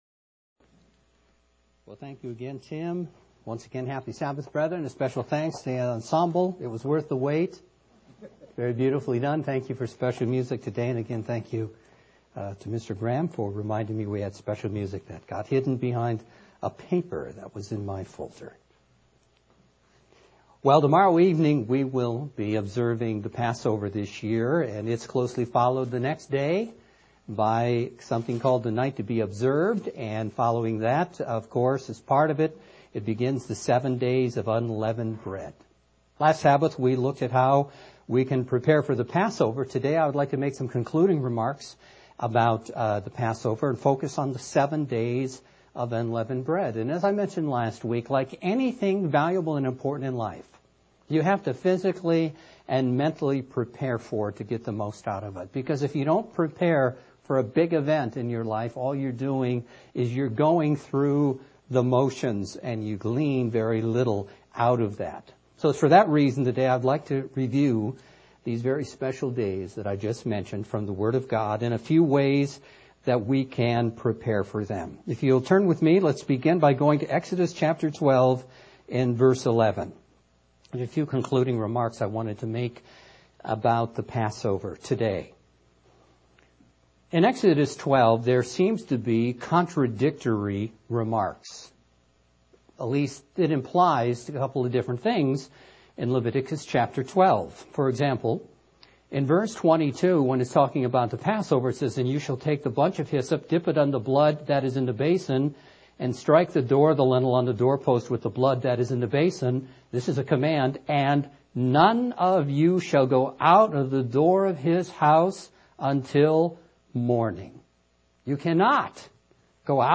Let's examine what God says, and plan to make the most of our Spring Holy Days UCG Sermon Transcript This transcript was generated by AI and may contain errors.